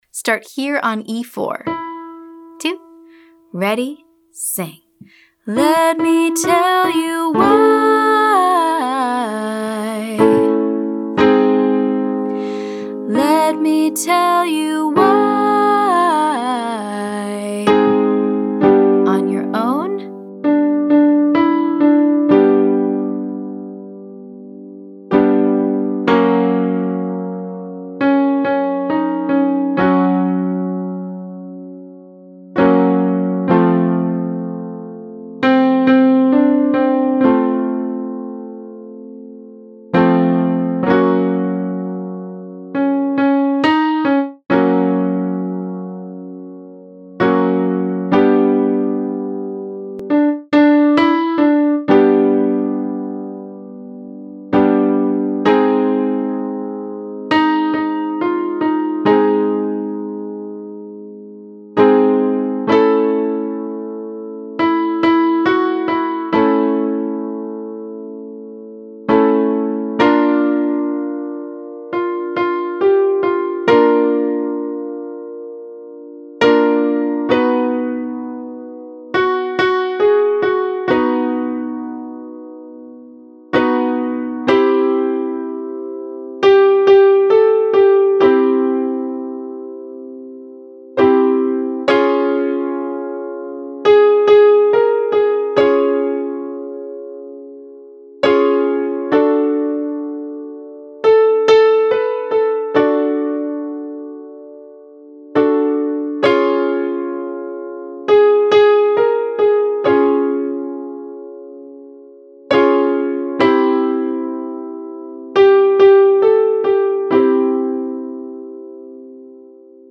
• Practice a confident leap followed by a pentatonic riff. Break it down slowly, then put it all together.